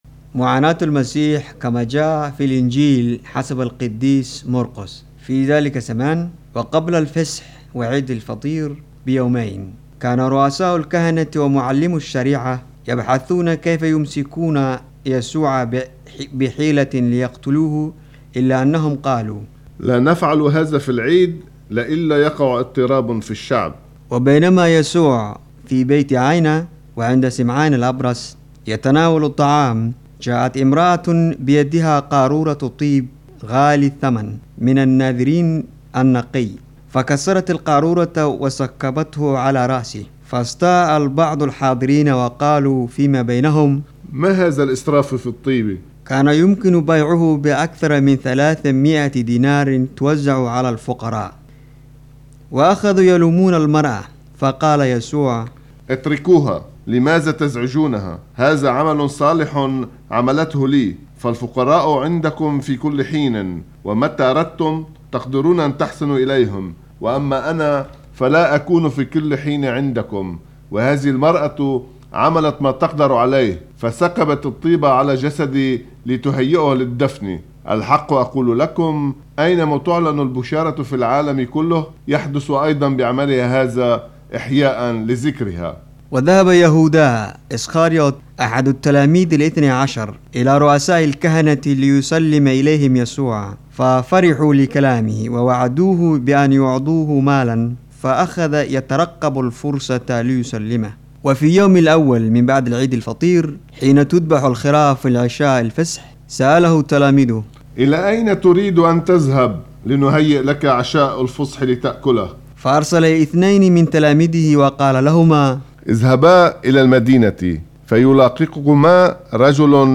Vangelo di domenica 1° aprile 2012: lettura in arabo – in collaborazione con PrimaRadio (Mp3, 18 Mb)